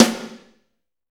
SNR F S S07R.wav